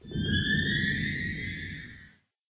cave10.ogg